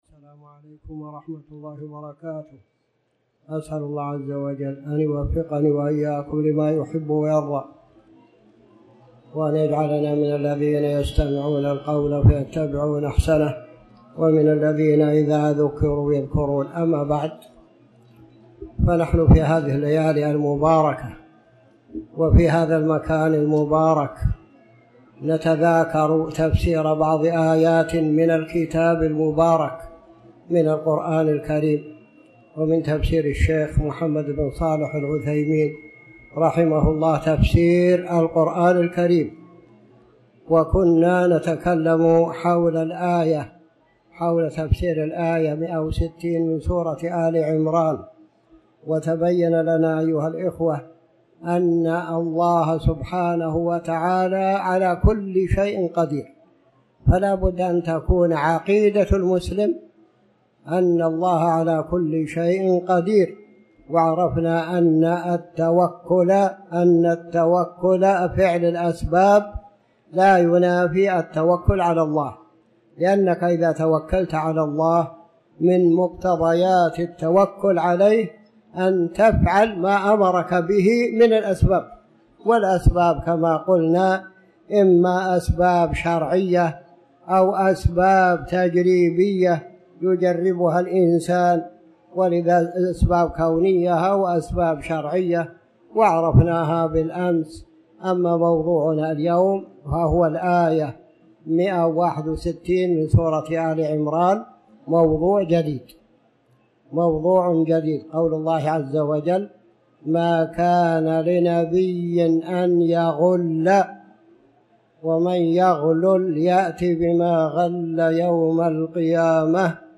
تاريخ النشر ٦ ربيع الأول ١٤٤٠ هـ المكان: المسجد الحرام الشيخ